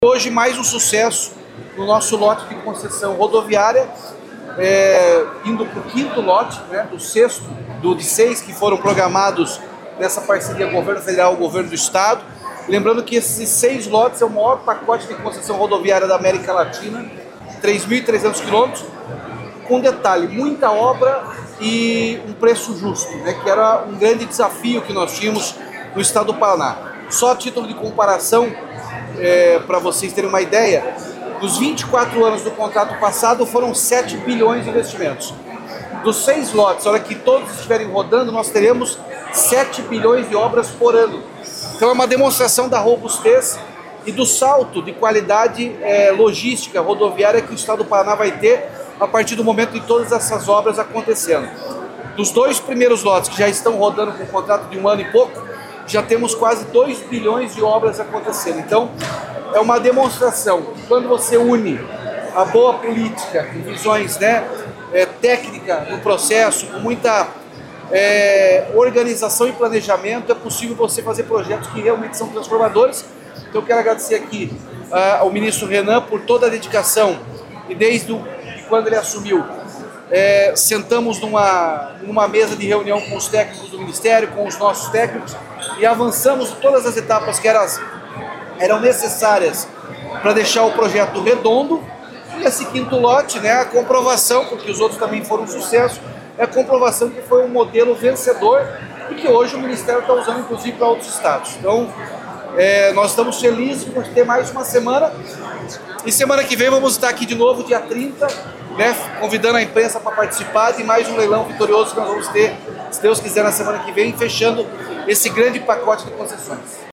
Sonora do governador Ratinho Junior sobre o leilão do Lote 4 das concessões rodoviárias do Paraná